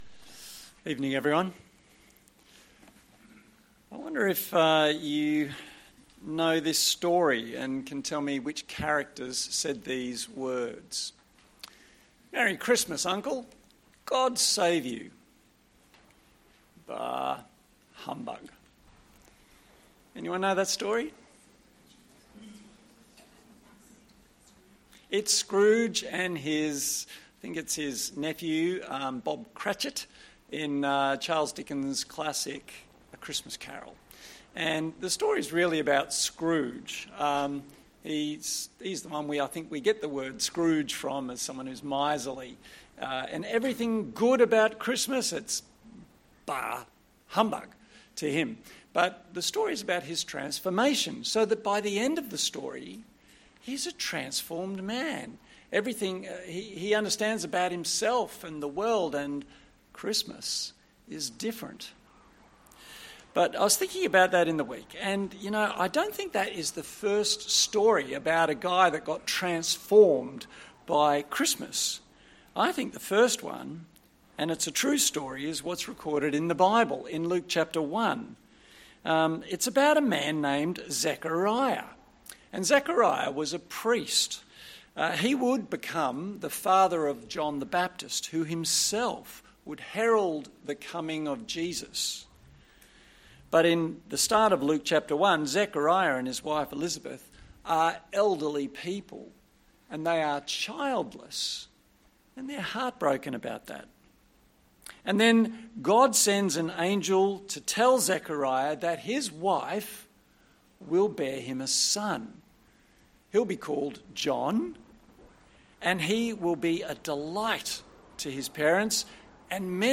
Christmas Passage: Luke 1:57-80 Service Type: Sunday Evening Topics